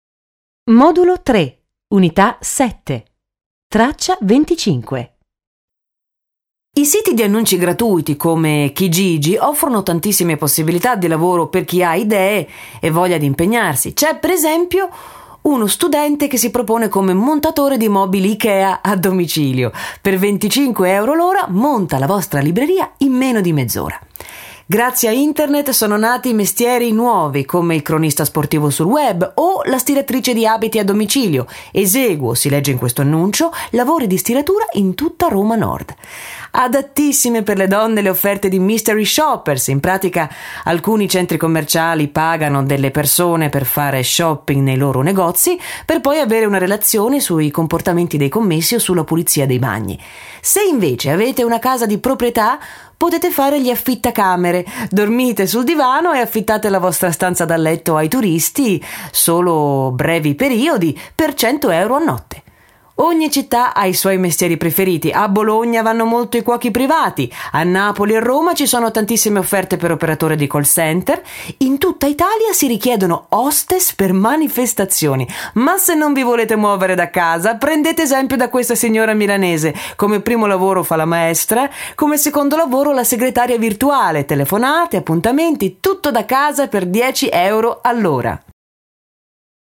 Ascolta questo servizio giornalistico e scrivi su un foglio tutti i mestieri che vengono nominati.